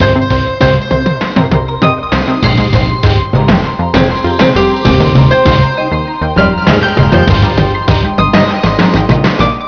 snd_1104_RambleEcho.11.wav